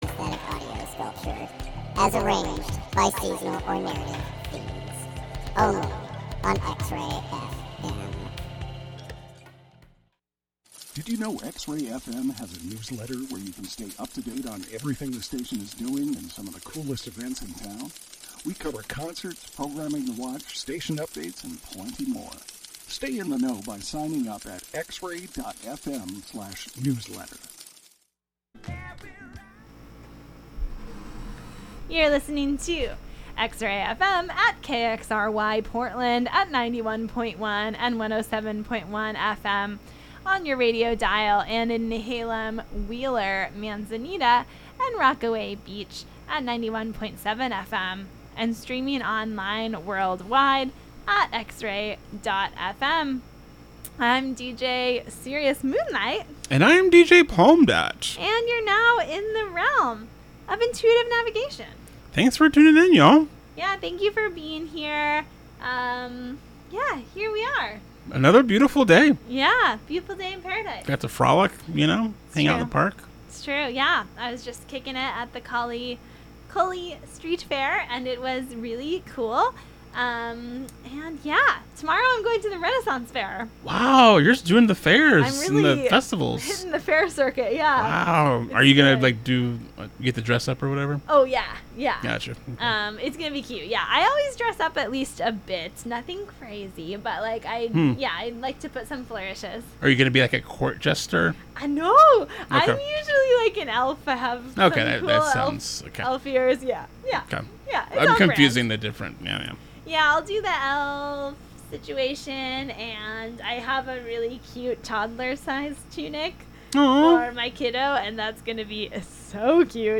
The latest in electronic pop, R&B, and other soulful music.
Feels-talk, moon-talk, and sultry jams.